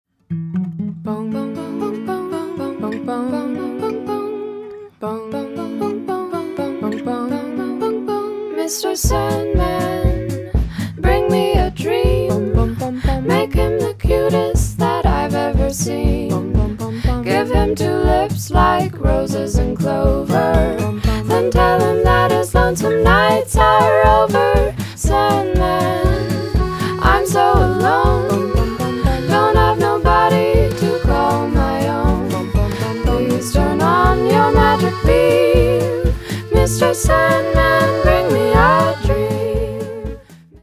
• Качество: 320, Stereo
женский вокал
веселые
джаз-фьюжн